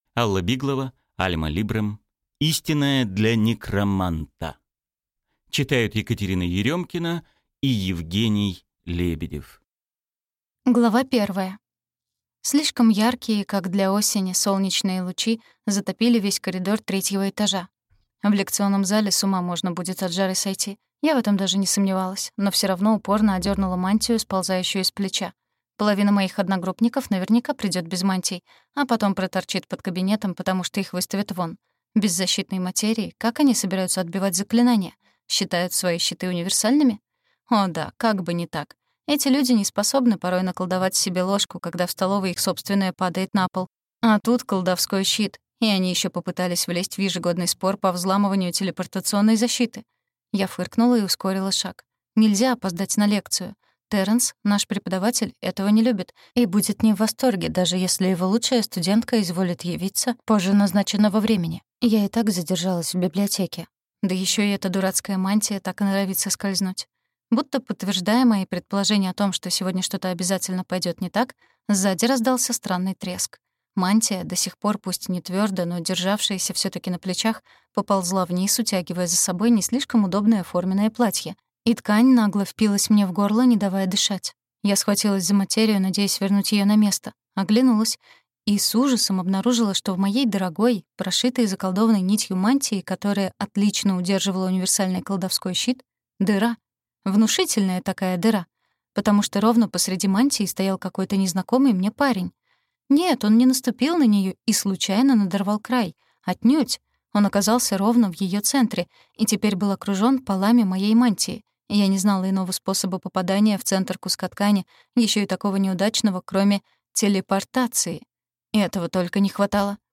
Аудиокнига Истинная для некроманта | Библиотека аудиокниг